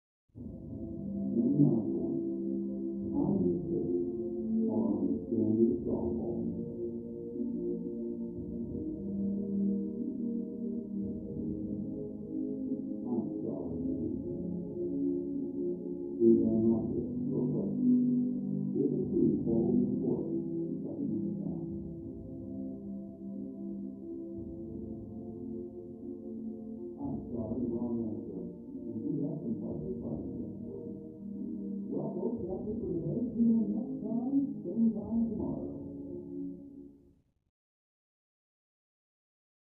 Television; Game Show Questions With Music And Announcer. Through Thick Wall.